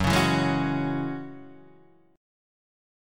F# 7th Suspended 2nd